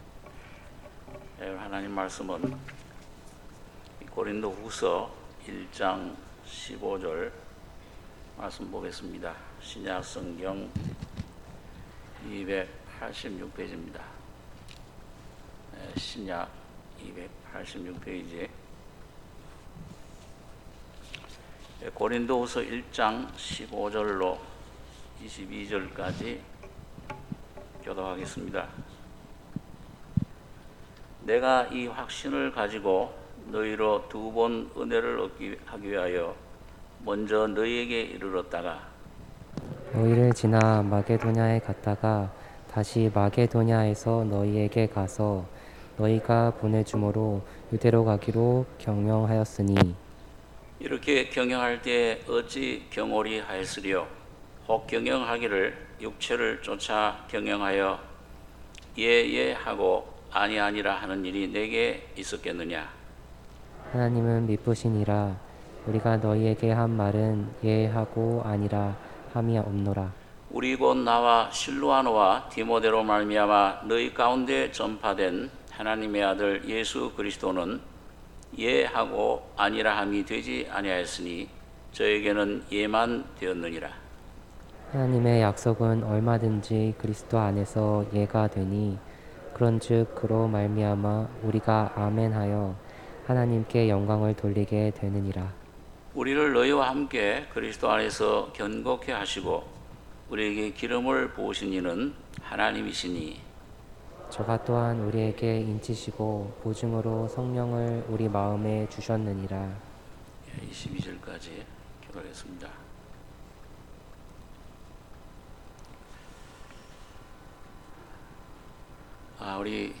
주일2부